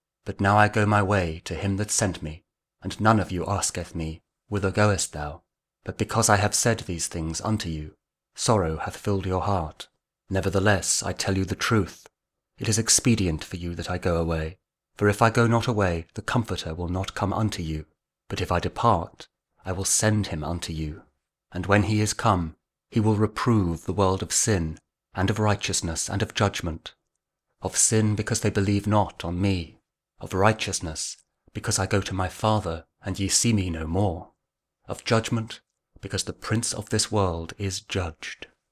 John 16: 5-11 | King James Audio Bible | KJV | King James Version